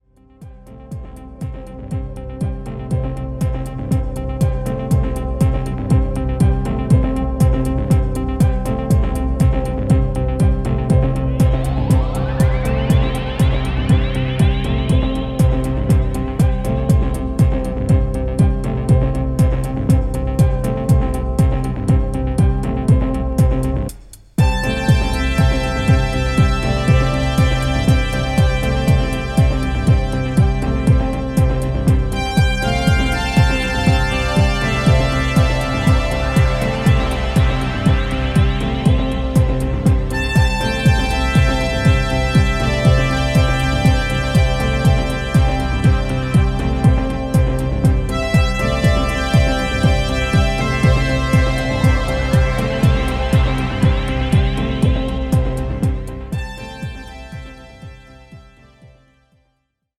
ホーム ｜ HOUSE / TECHNO > HOUSE
シンプルでミニマルなビートの中に中東らしいエスノ味やダスティな空気感が滲み出ているところが魅力的な1枚です。